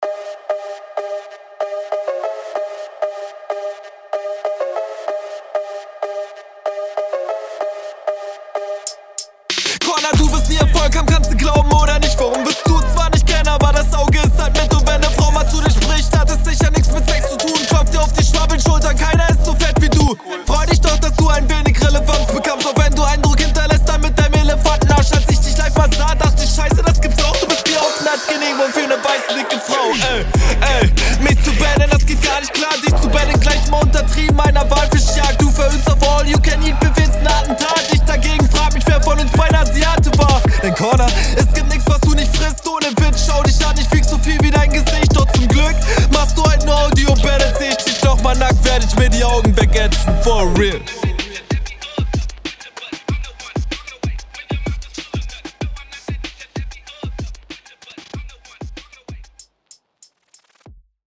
Interessanter Beat!
Anstrengender Beat.
Der Beat ist auch wieder pervers gut.